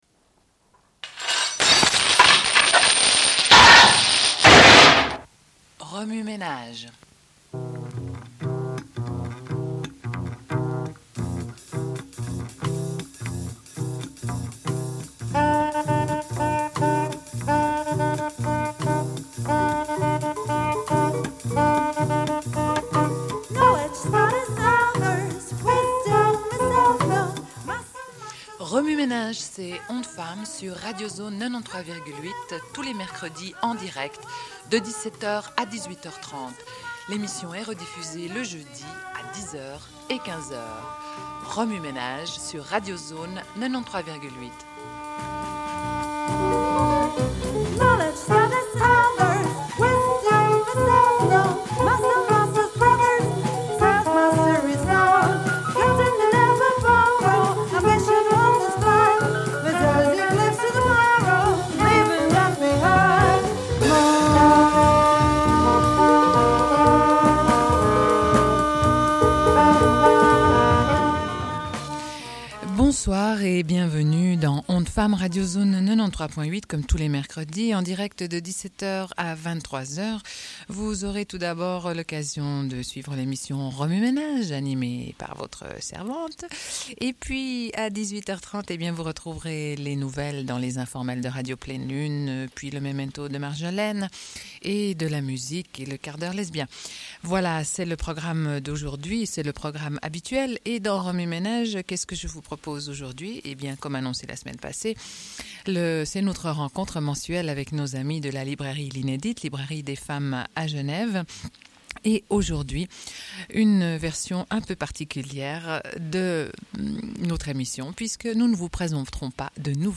Une cassette audio, face A31:37